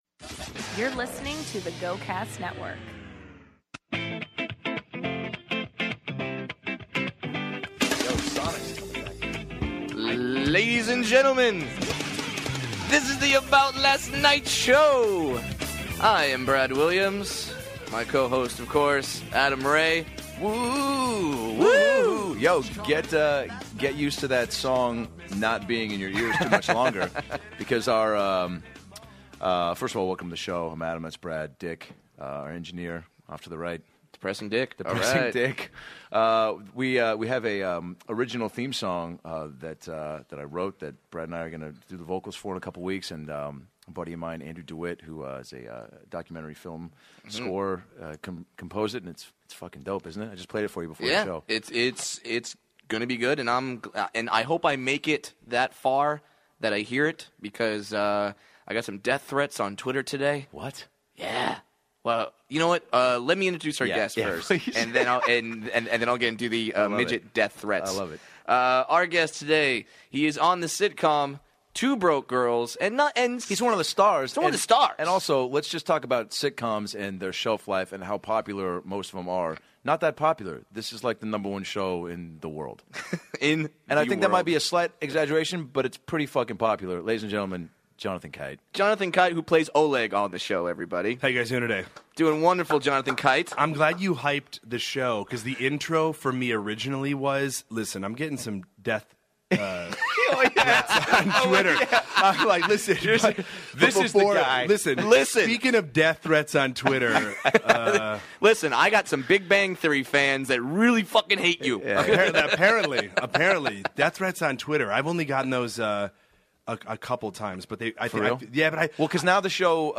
Two Broke Girls star and impressionist Jonathan Kite (Oleg) joins the guys to talk Tom Hanks and why Dr. Claw needs a reality show.